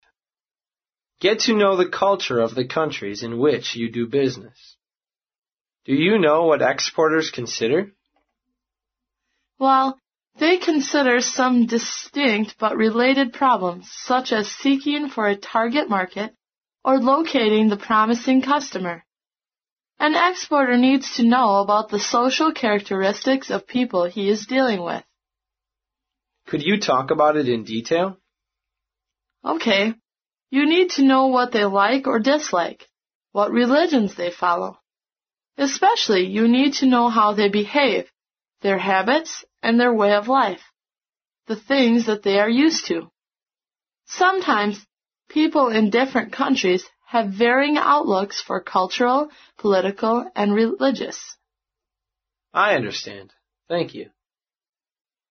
在线英语听力室外贸英语话题王(MP3+中英字幕) 第36期:了解对方国家文化的听力文件下载,《外贸英语话题王》通过经典的英语口语对话内容，学习外贸英语知识，积累外贸英语词汇，潜移默化中培养英语语感。